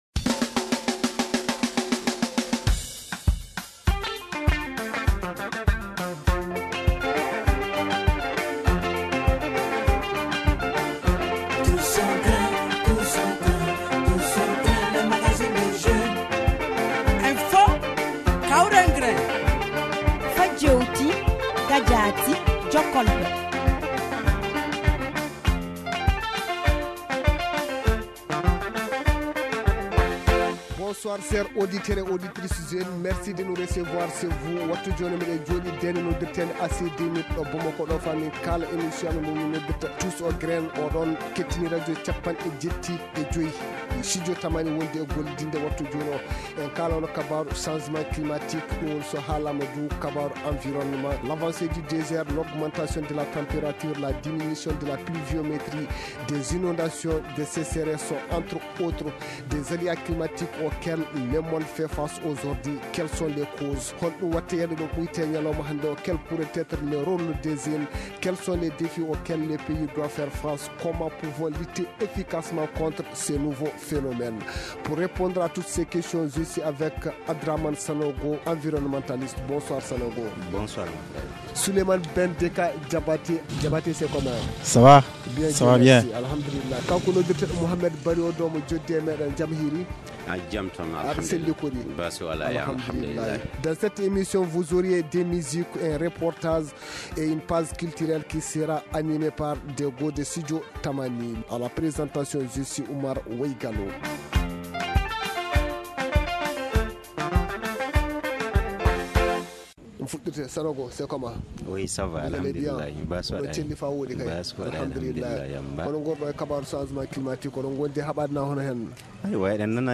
Quel pourrait être le rôle des jeunes ? Le Tous au grin pose le débat.